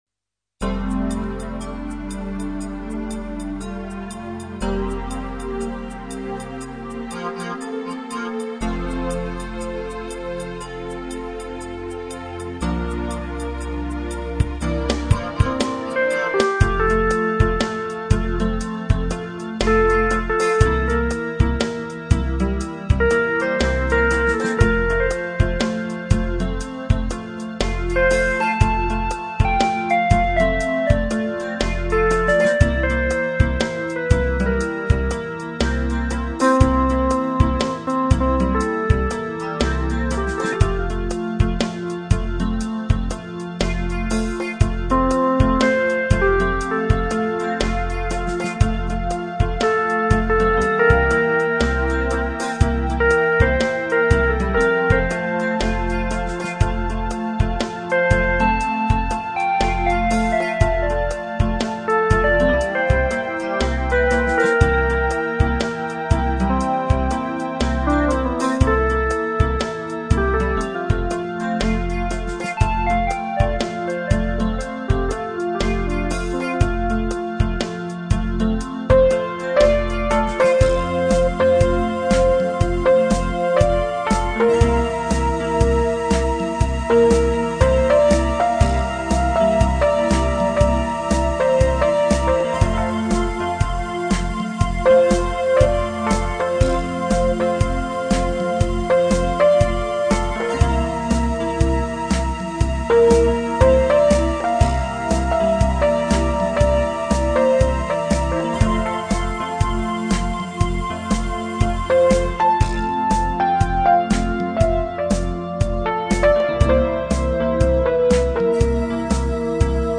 Composizioni che evidenziano temi cantabili e armoniosi.